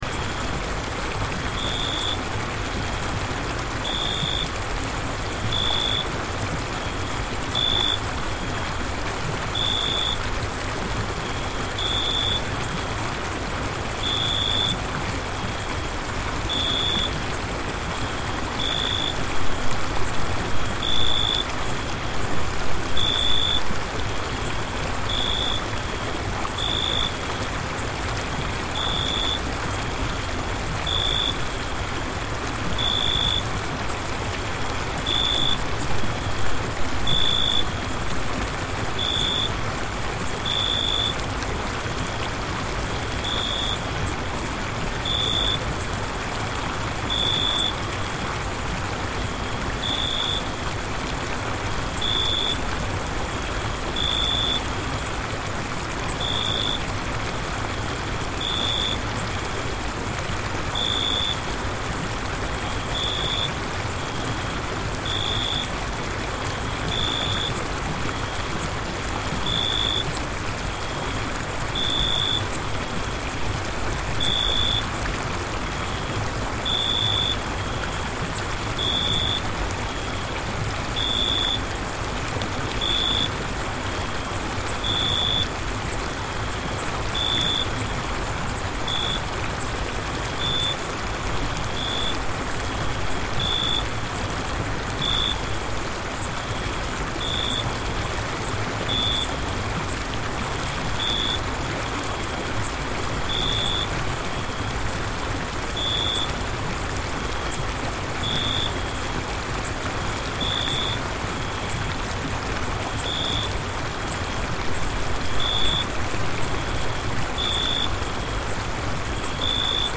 Relaxing Sounds / Sound Effects 24 Jan, 2026 Relaxing Summer Forest Ambience Read more & Download...
Relaxing-summer-forest-ambience.mp3